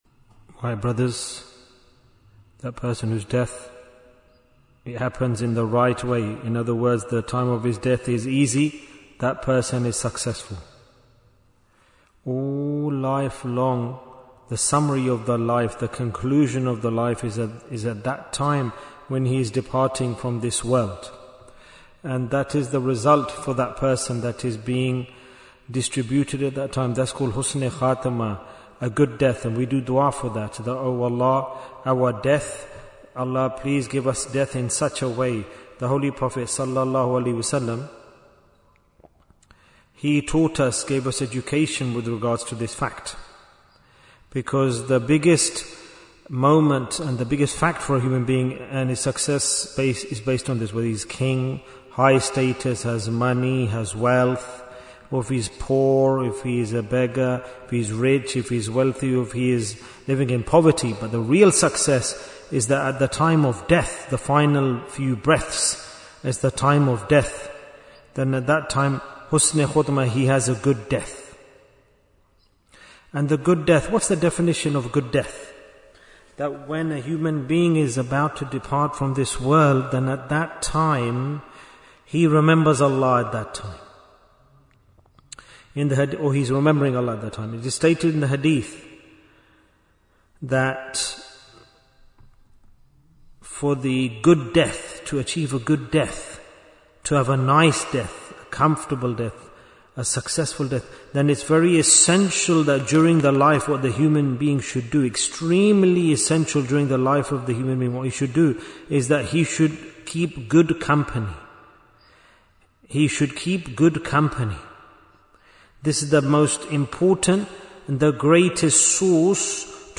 Talk before Dhikr 2011 minutes31st May, 2025